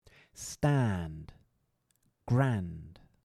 stand – /stæːnd/ vs. grand – /ɡrænd/